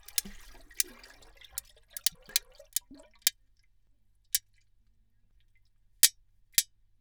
Mais alors que les chercheurs manipulaient brièvement avec leurs mains dix émissoles tachetées en captivité, l’hydrophone placé dans le bassin a étrangement mesuré des “clics” courts et répétés produits par ces petits requins néo-zélandais.
En moyenne, chaque requin a émis neuf “clics” sur une période de vingt secondes. Ces sons se distinguaient par une large bande de fréquence, atteignant jusqu’à 24 kHz, et une durée moyenne de 48 millisecondes. Certains clics comportaient une seule impulsion, tandis que d’autres, deux successives. L’intensité sonore des impulsions initiales dépassait fréquemment les 160 dB, un niveau suffisamment élevé pour être perceptible même dans un environnement bruyant.
Ecoutez les clics émis par une émissole tachetée :